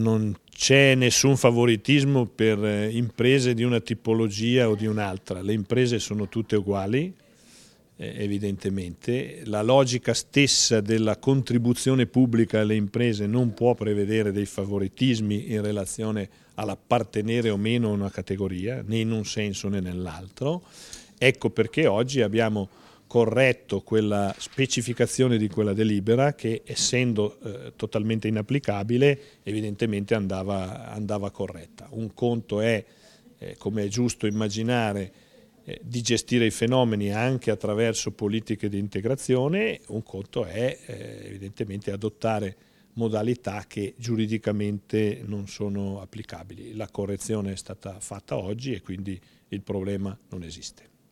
Tale specificazione, come ha spiegato oggi durante la conferenza stampa post Giunta, il presidente della Provincia Ugo Rossi è stata cancellata in quanto inapplicabile, perché migranti e richiedenti asilo dovrebbero essere regolarmente iscritti al registro delle imprese della Camera di Commercio e quindi essere in possesso del permesso di soggiorno per lo svolgimento di attività autonoma.